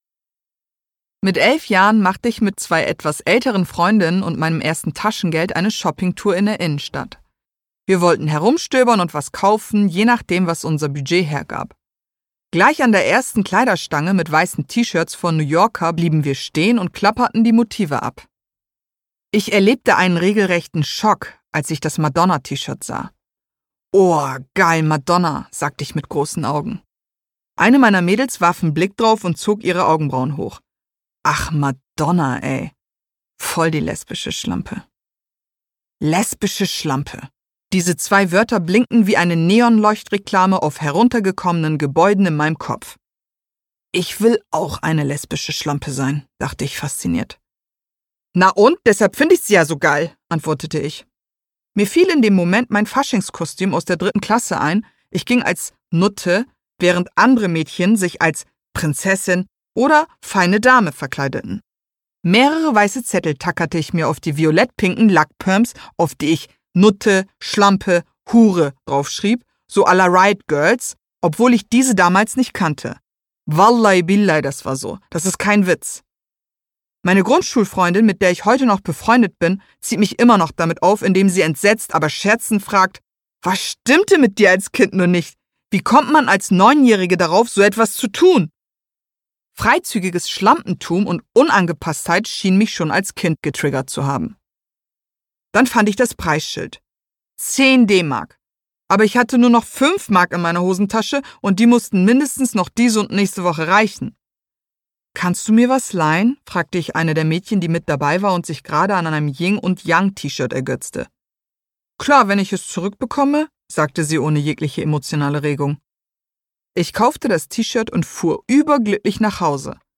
Lady Bitch Ray über Madonna Lady Bitch Ray (Autor) Lady Bitch Ray (Sprecher) Audio-CD 2020 | 1.